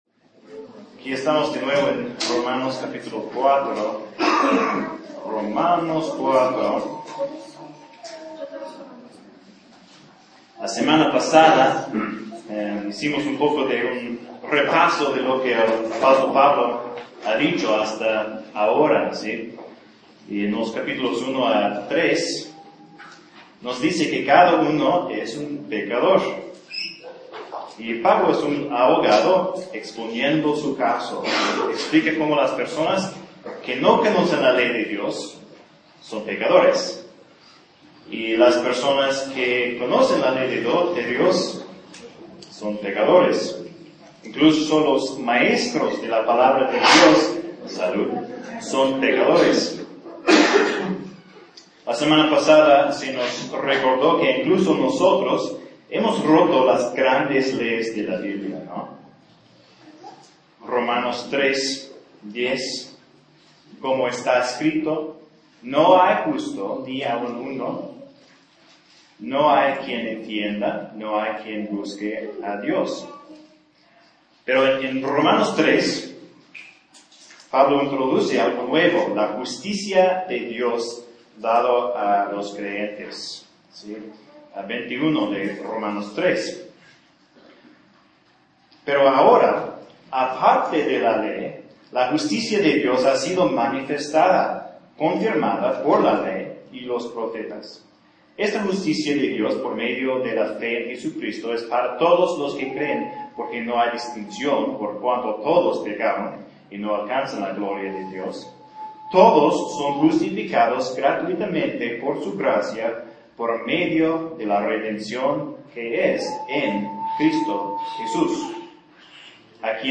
Esta es la segunda parte de un sermón de dos partes acerca de Romanos capítulo 4.